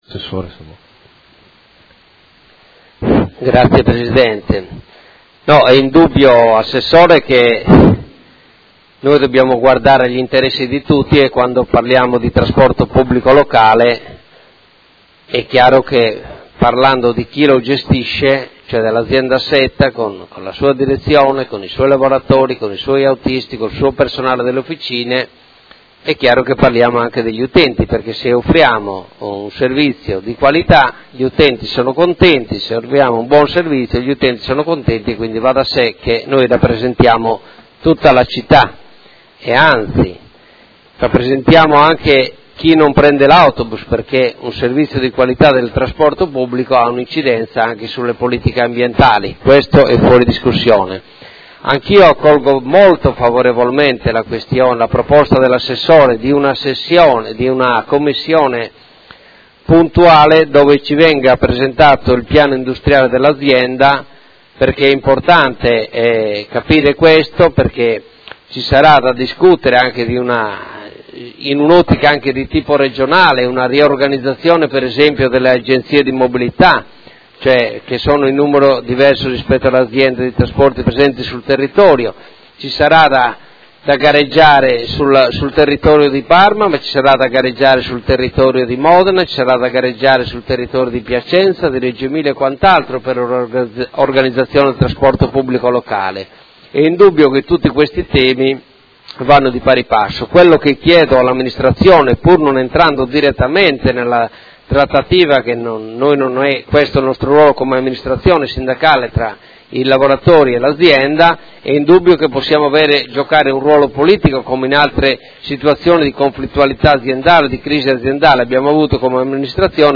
Seduta del 20/04/2016. Dibattito su interrogazione del Consigliere Cugusi (SEL), Chincarini e Campana (Per Me Modena) e Rocco (FAS – Sinistra italiana) avente per oggetto: Quale futuro per il trasporto pubblico locale?